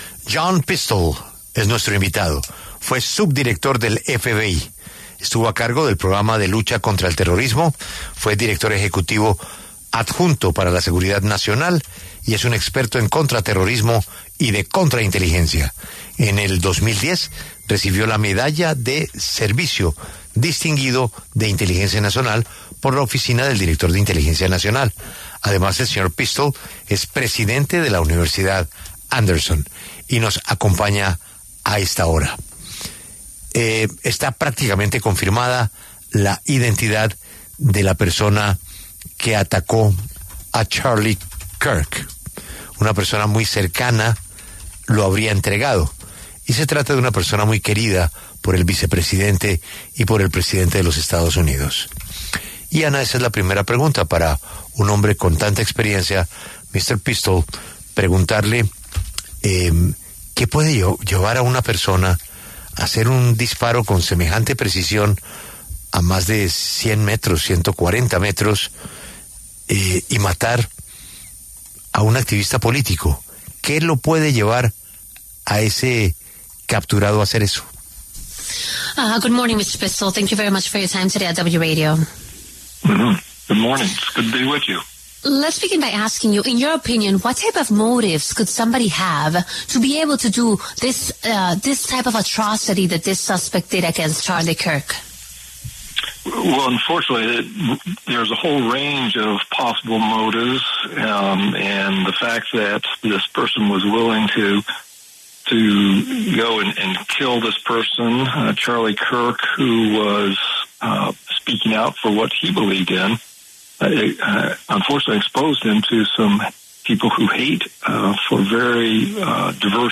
John Pistole, quien se desempeñó como subdirector de la Oficina Federal de Investigación (FBI) de 2004 a 2010, conversó con La W luego de que se anunciara que fue detenido el supuesto asesino de Charlie Kirk, quien recibió un disparo en un campus universitario de Utah.